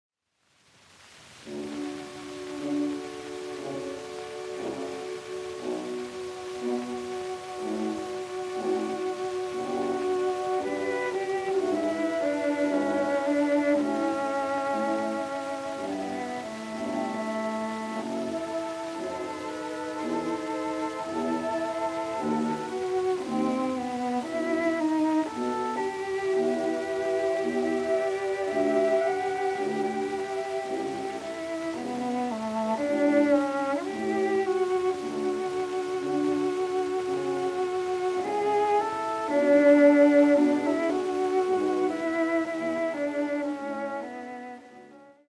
古い録音で聴くチェロの響きの奥深さよ。
録音：1916〜20年　モノラル録音